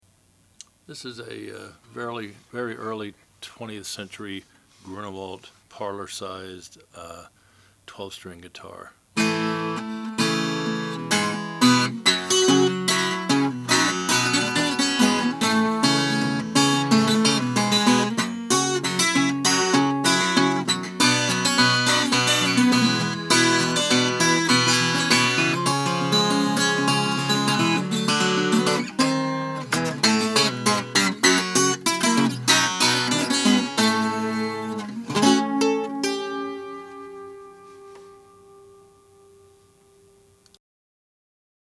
~1905 Grunewald 12-String Natural
With a short scale, low action and tuned down a step-and-a-half, the Grunewald 'parlor 12' is a pleasure to play, perfect for ragtime and country blues.
The sound is bright and jangly, with enough punch in the mid-range and bass to satisfy most ragtime pickers.